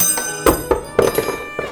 Sounds I need to do something with - someone dropping a triangle in a concert :)